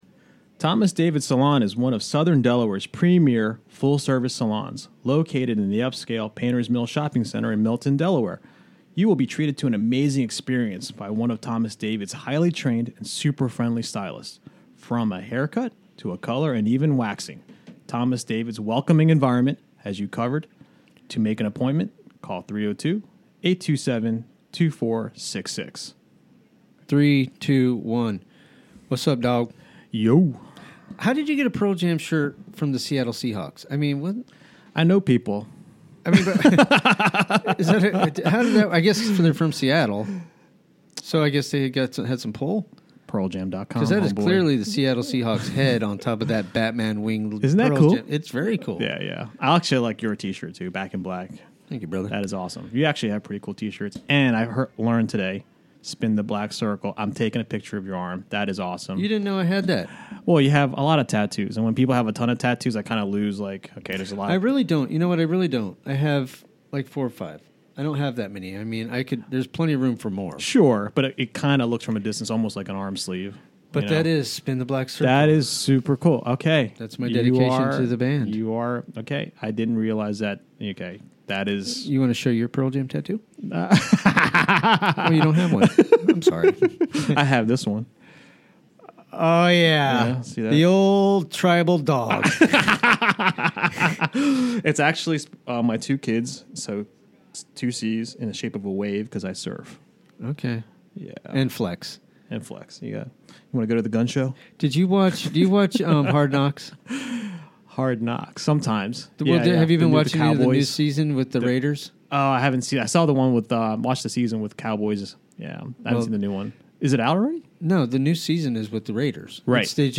Chatting with local folks